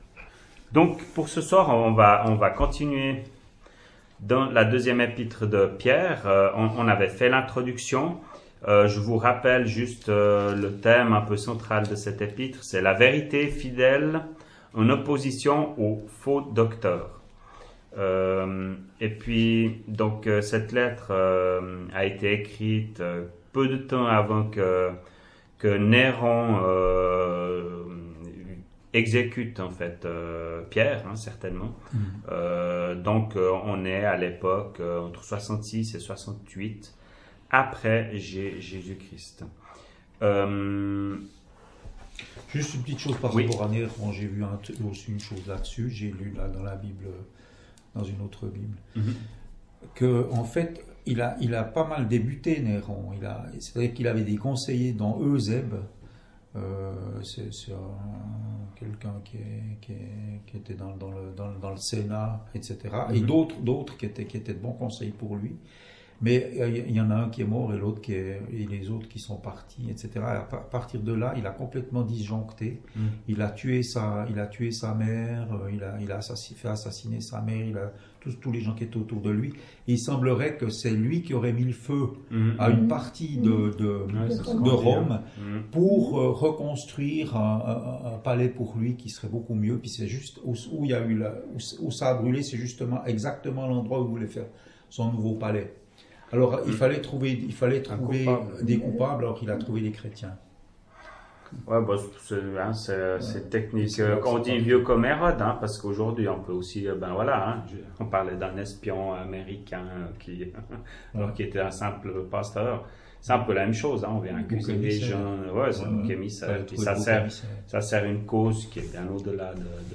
[Chapelle de l’Espoir] - Étude biblique : Deuxième Épître de Pierre, 2ème partie